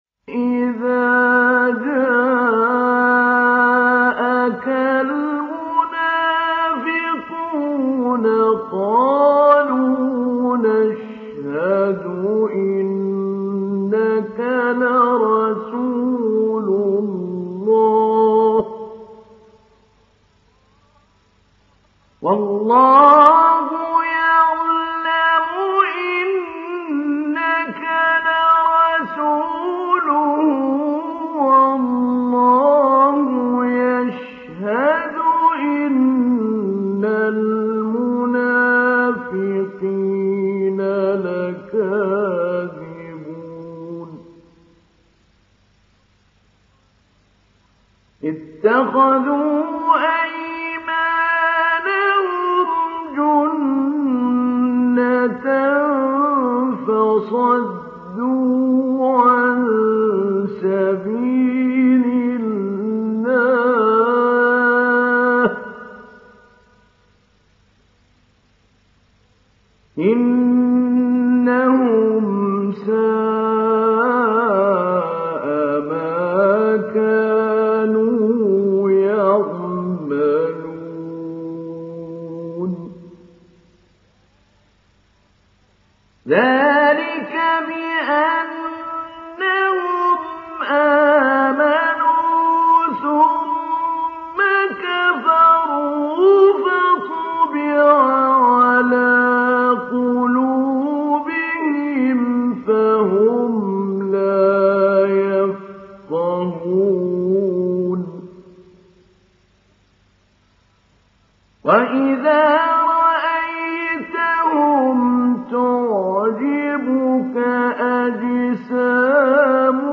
دانلود سوره المنافقون mp3 محمود علي البنا مجود (روایت حفص)